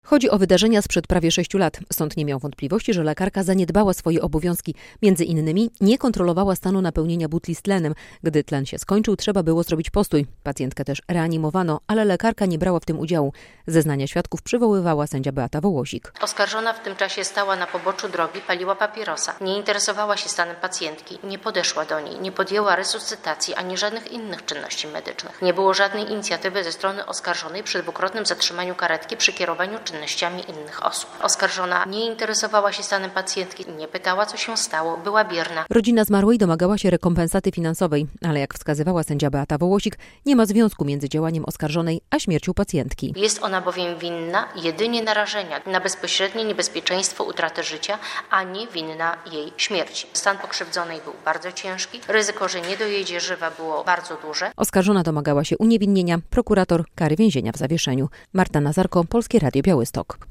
z sądu relacja